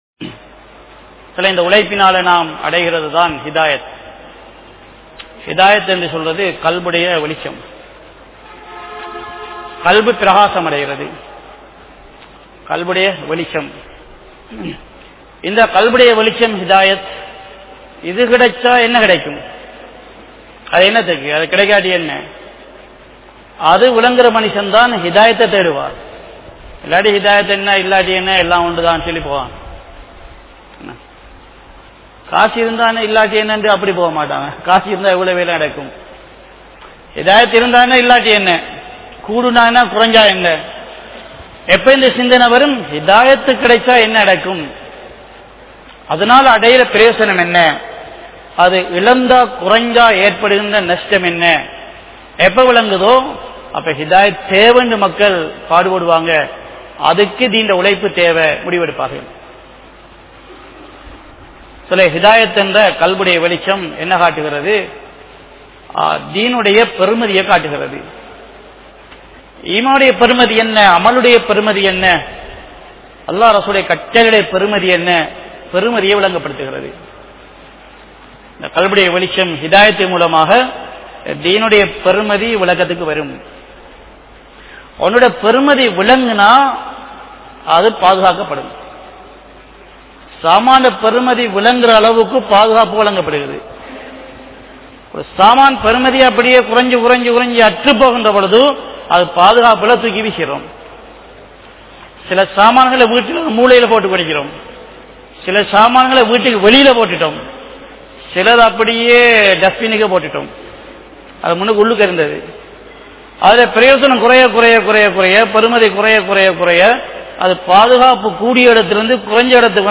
Suvarkaththai Noakkiya Paathai(சுவர்க்கத்தை நோக்கிய பாதை) | Audio Bayans | All Ceylon Muslim Youth Community | Addalaichenai
Line Jumua Masjith